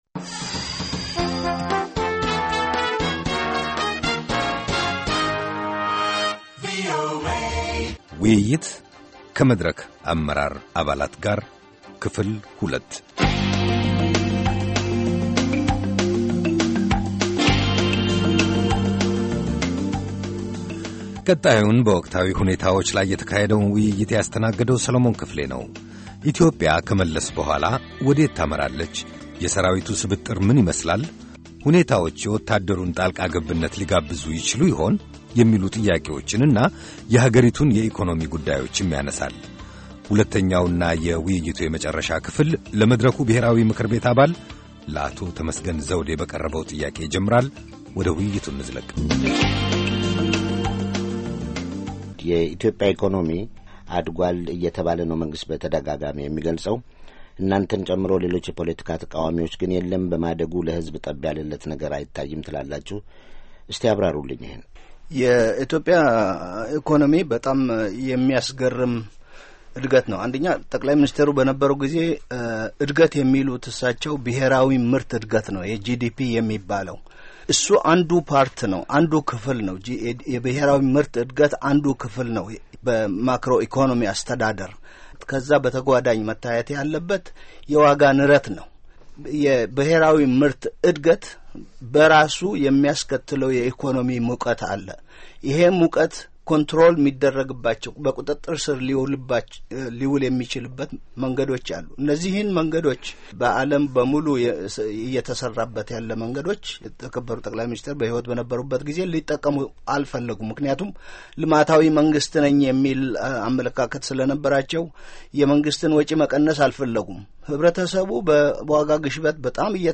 ውይይት ከመድረክ አመራር አባላት ጋር፣ የወቅቱ የኢትዮጵያ ፖለቲካ